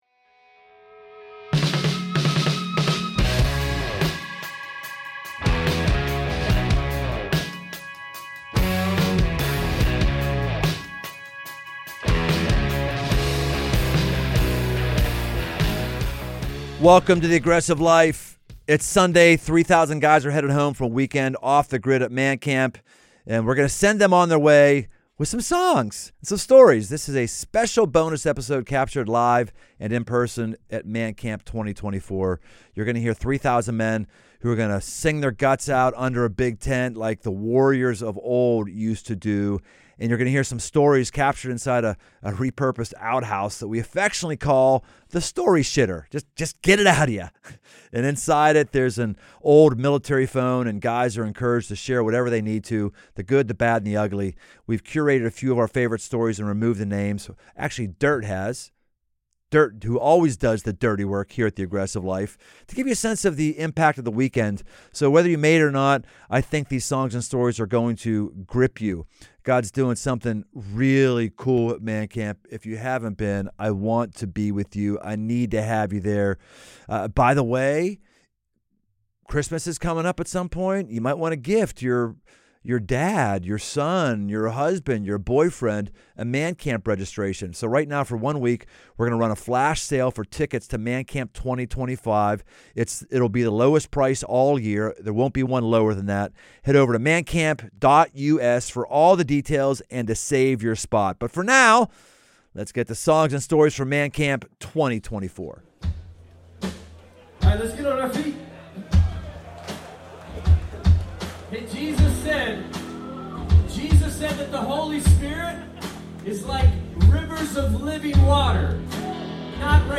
It's Sunday, and 3,000 guys are heading home from a MAN CAMP for the record books—more rain, more laughter, more mud, and more lives changed than ever before. This bonus episode features a mix of live worship, and stories collected in the Story Shitter.